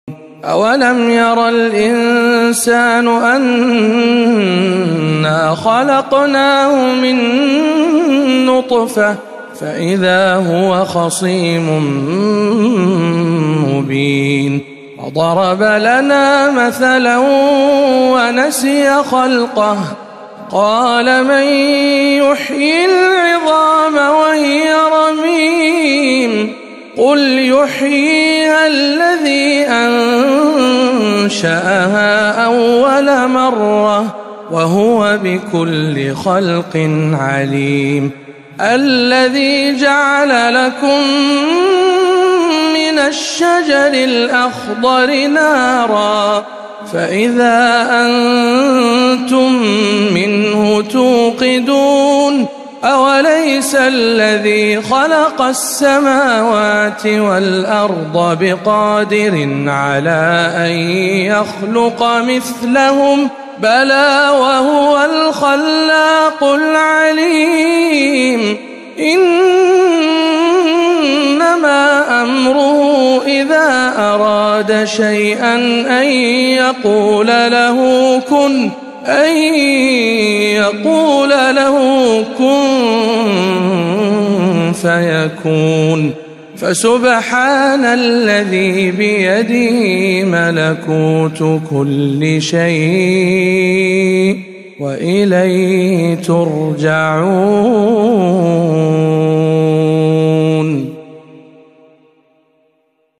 تلاوة مميزة من سورة يس